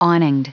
Prononciation du mot awninged en anglais (fichier audio)
Prononciation du mot : awninged